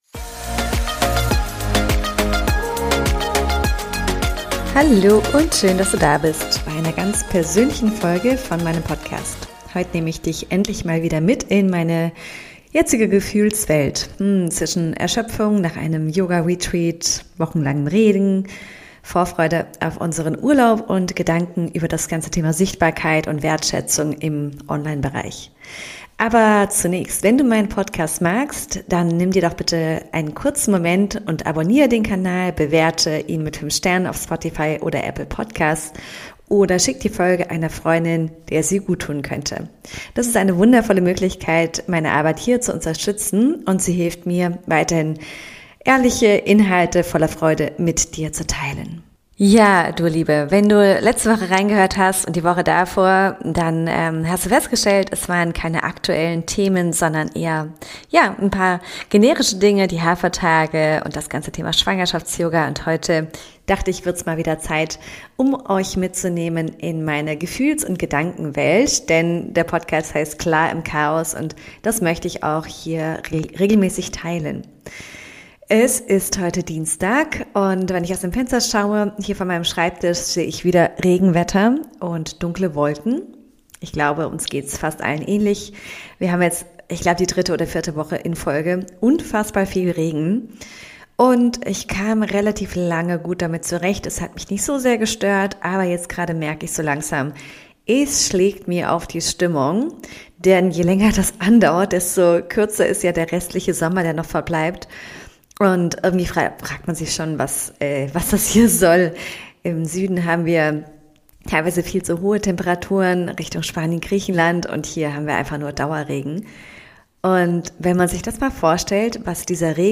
Beschreibung vor 8 Monaten In dieser ehrlichen Solo-Folge nehme ich dich mit in mein Inneres: Ich spreche über meine Erschöpfung nach einem intensiven Retreat-Wochenende, die Stimmung, die das Dauerregenwetter mit sich bringt, und den Druck, der oft entsteht, wenn ich online so wenig Rückmeldung bekomme – obwohl so viele Menschen still mitlesen oder zuhören. Ich teile, was das mit mir macht, warum ich manchmal Yoga-Beiträge lösche – und wie wichtig echte Verbindung für mich ist.